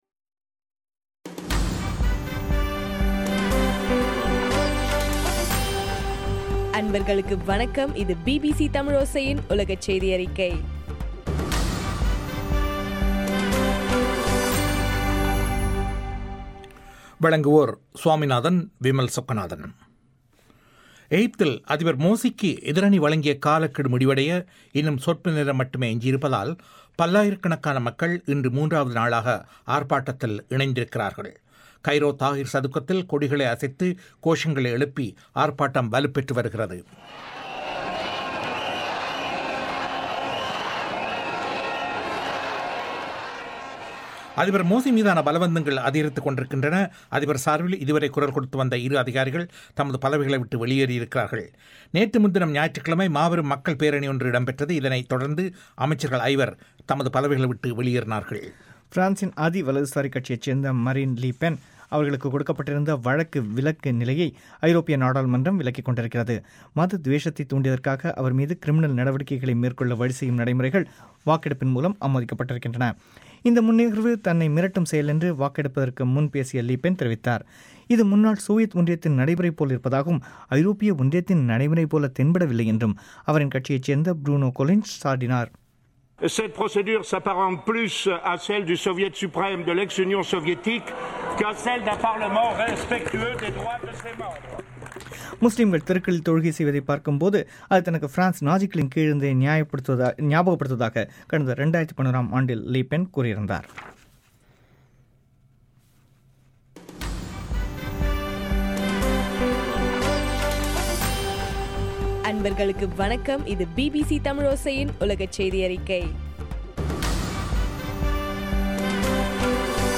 ஜுலை 2 தமிழோசை உலகச் செய்தி அறிக்கை